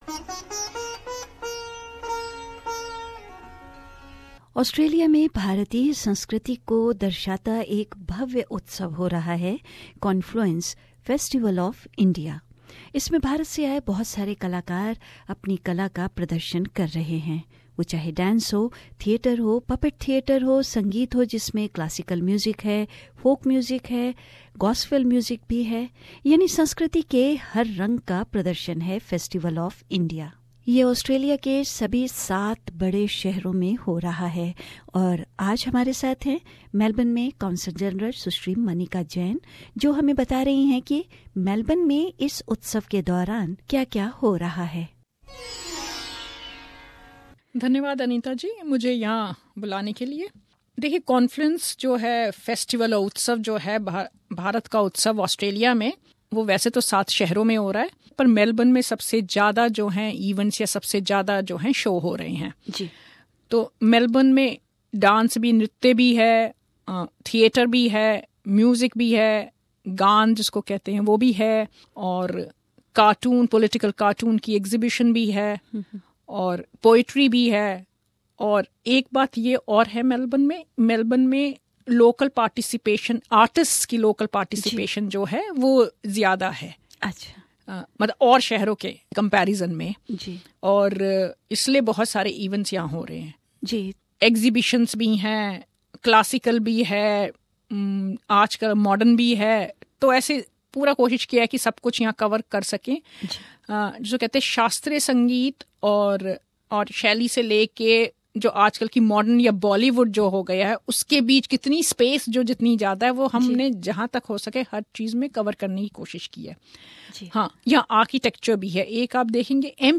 बातचीत में, इस उत्सव के कार्यक्रमों के बारे में जानकारी दी।